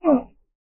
male_drown3.ogg